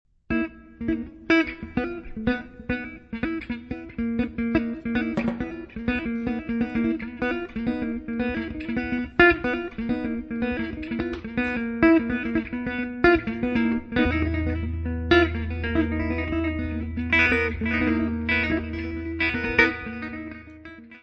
guitarra
baixo
bateria.
Área:  Novas Linguagens Musicais